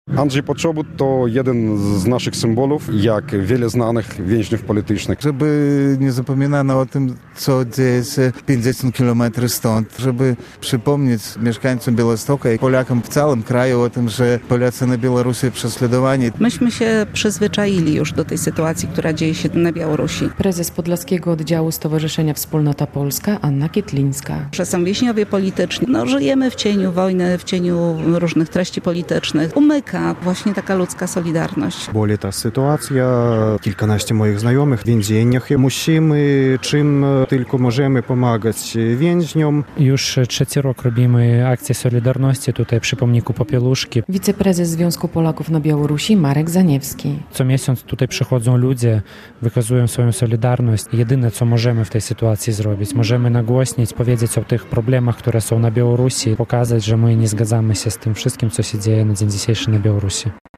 Apelowali o wolność dla Andrzeja Poczobuta i wszystkich więźniów politycznych na Białorusi. Przy pomniku księdza Jerzego Popiełuszki w Białymstoku, gdzie od wielu miesięcy stoi symboliczny portret Andrzeja Poczobuta, Polacy i Białorusini uczestniczyli w kolejnej akcji solidarności.
Musimy mówić o tym, ile zła dzieje się na Białorusi - podkreślali uczestnicy akcji.